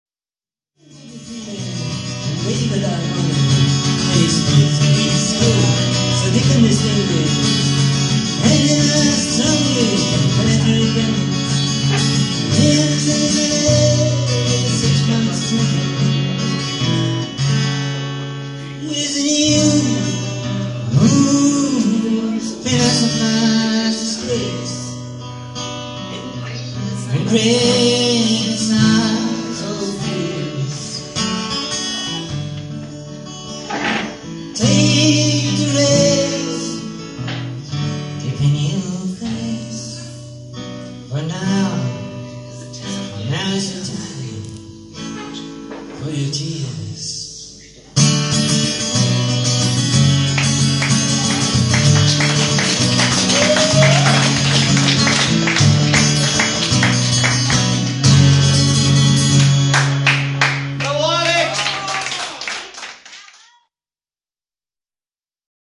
Malga Ime (Verona - Italy)